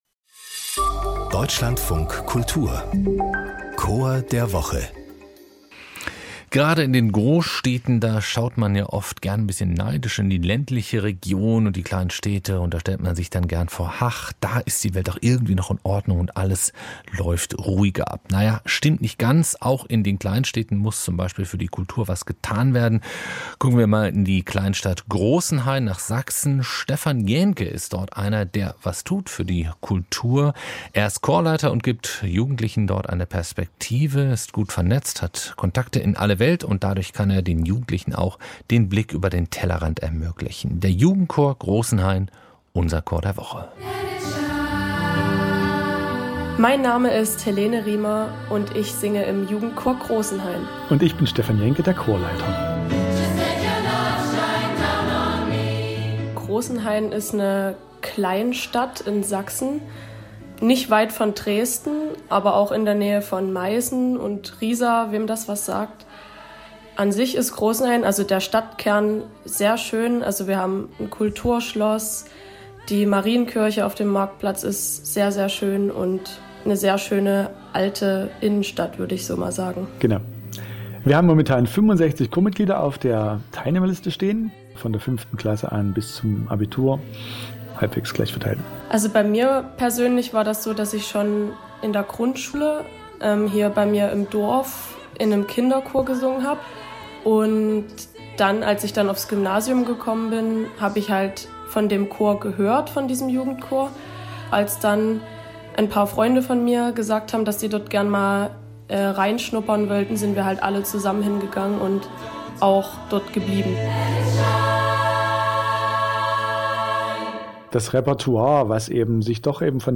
Jugendchor Großenhain